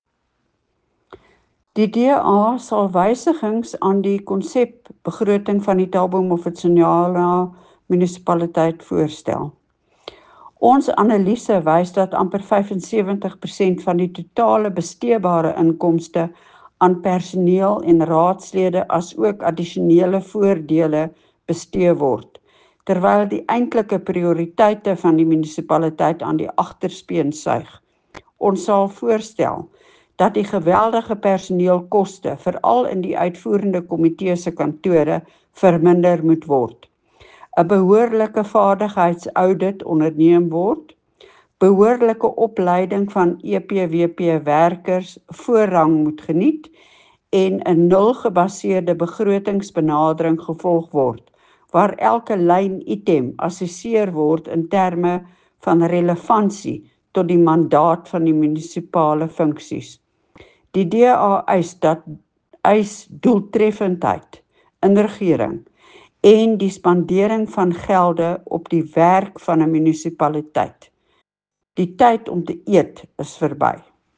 Afrikaans soundbites by Cllr Leona Kleynhans and